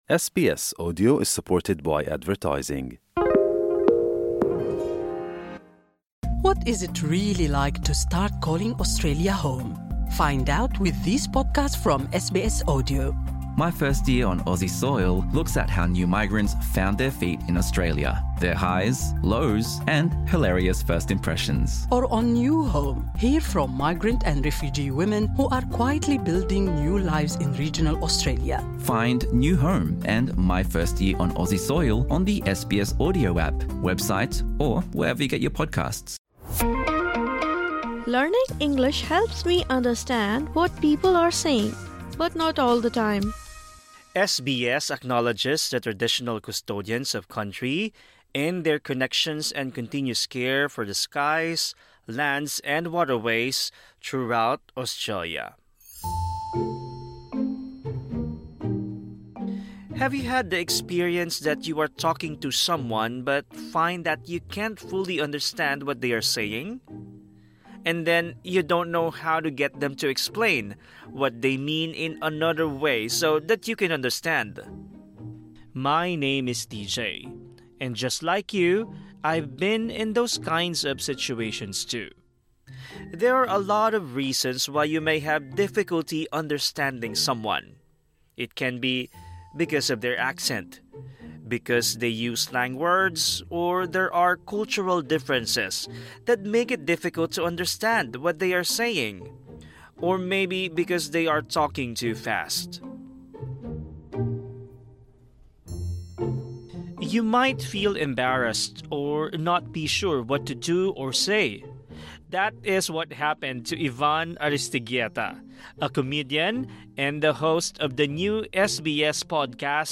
Learn how to say you don’t understand. Plus, hear a comedian’s funny story about misunderstanding an Australian idiom.